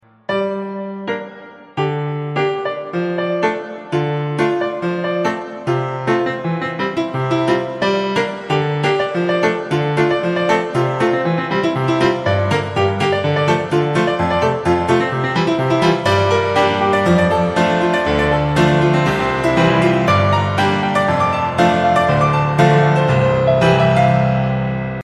• Качество: 128, Stereo
веселые
без слов
инструментальные
пианино